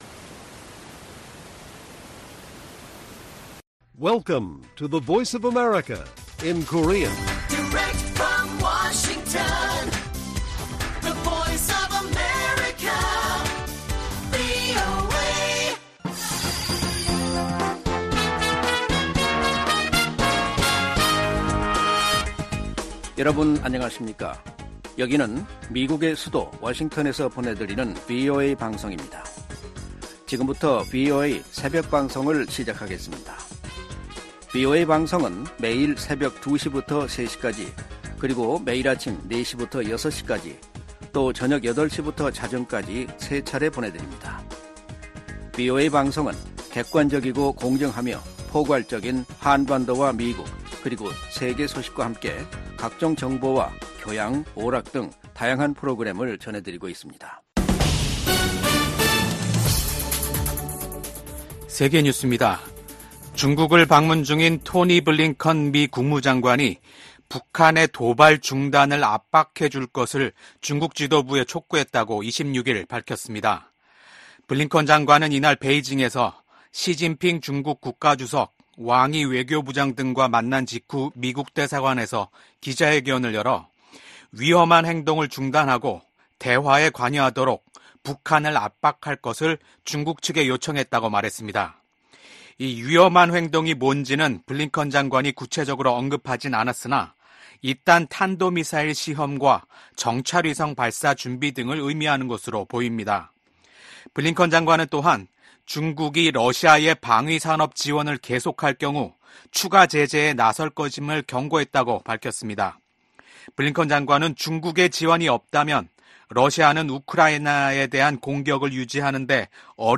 VOA 한국어 '출발 뉴스 쇼', 2024년 4월 27일 방송입니다. 미국과 한국, 일본이 제14차 안보회의를 열고 지속적인 3국간 안보협력 의지를 재확인했습니다. 유엔 주재 미국 부대사는 중국과 러시아의 반대로 북한의 핵 프로그램에 대한 조사가 제대로 이뤄지지 못했다고 지적했습니다. 북한이 김정은 국무위원장이 참관한 가운데 신형 240mm 방사포탄 검수사격을 실시했습니다.